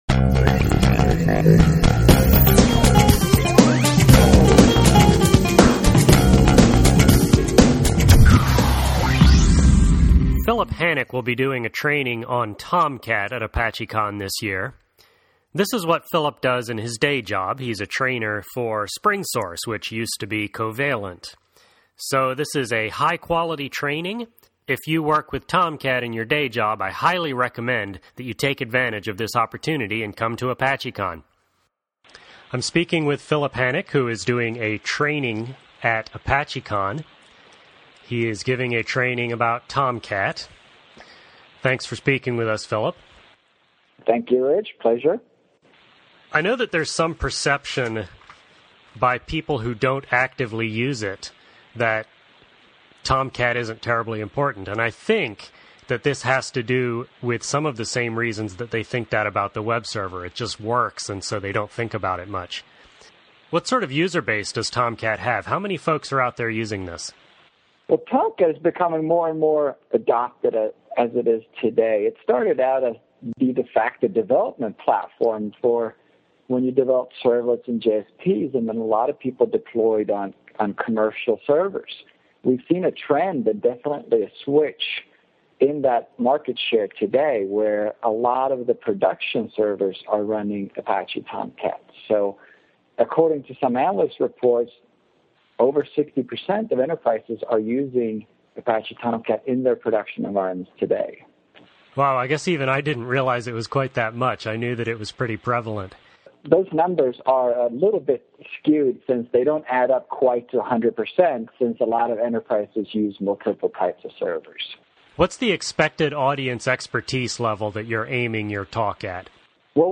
I spoke with him last week about his training class, and about other content at Apachecon that complements it.